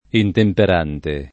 intemperante [ intemper # nte ] agg.